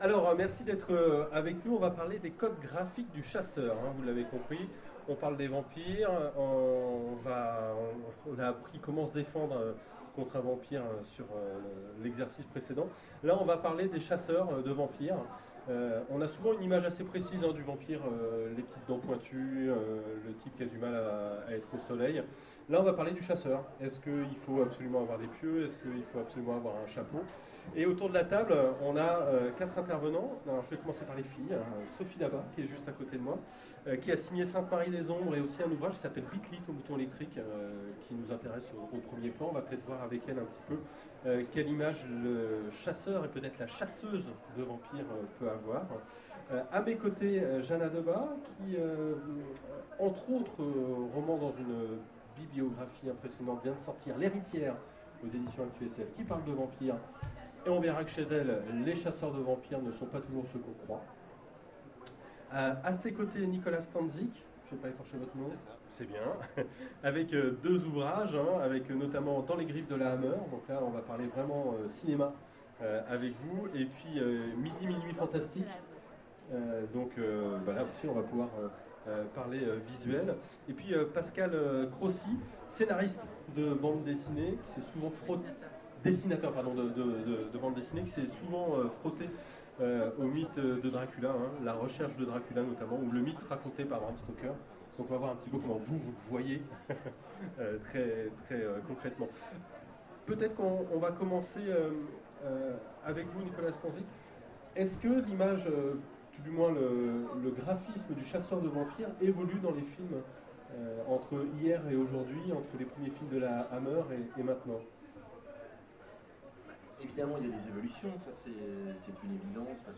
Salon du vampire 2014 : Conférence Quels codes graphiques pour le chasseur ?